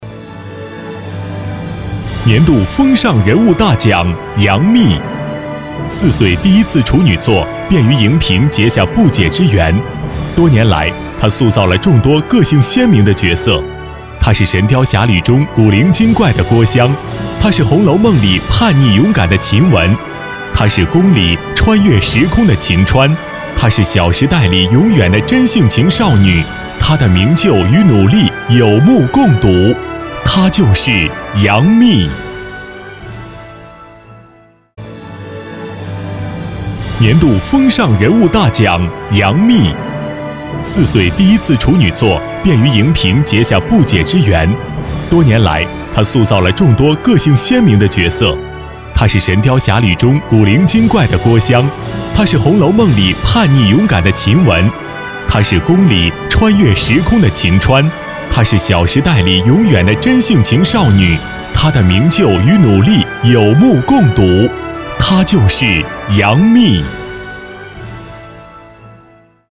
国语青年激情激昂 、大气浑厚磁性 、沉稳 、男宣传片 、颁奖主持 、80元/分钟男S331 国语 男声 晚会颁奖-年度风尚大奖 激情激昂|大气浑厚磁性|沉稳